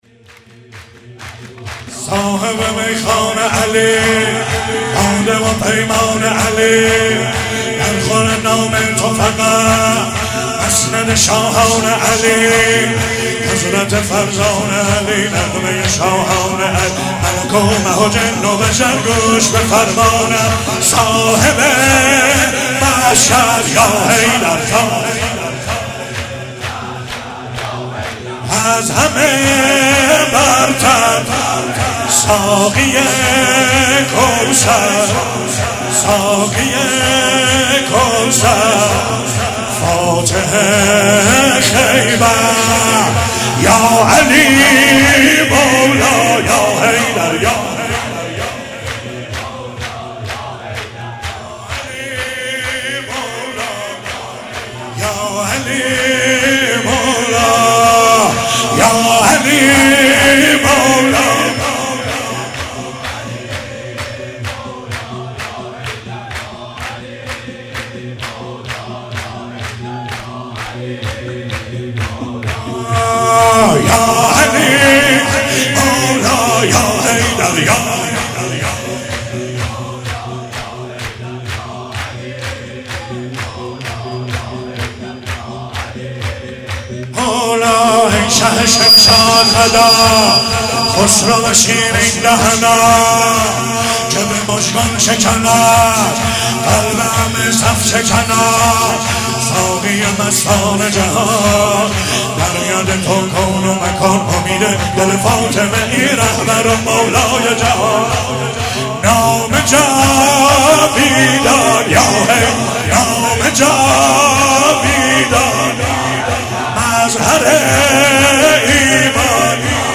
ولادت حضرت زهرا(س)97 - سرود - صاحب میخانه علی